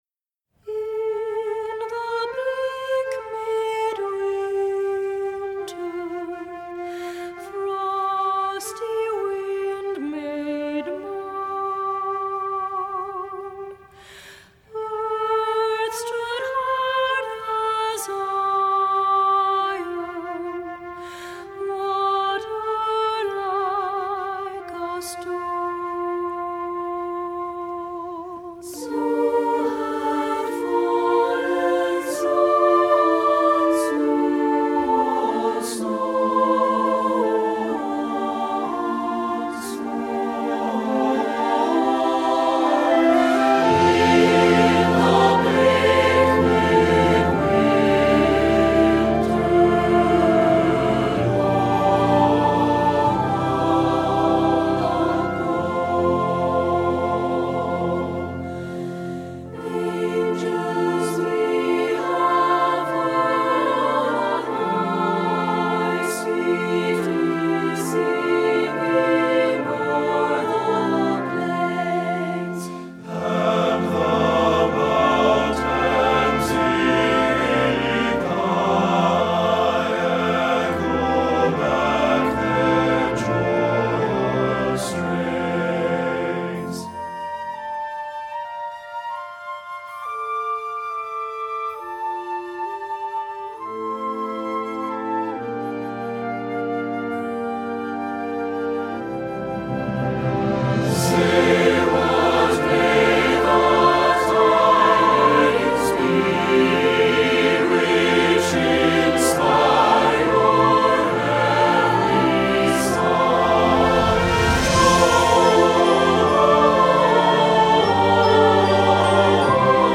• Soprano
• Alto
• Tenor
• Bass
• Keyboard
Studio Recording
Ensemble: Mixed Chorus
Accompanied: Accompanied Chorus